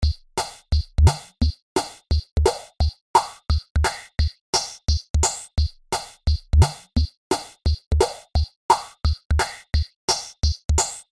A parametric EQ is used upon the drums in several parts of the track, sweeping up through the frequencies of the course of 4 bars.
Parametric EQ Sweep.mp3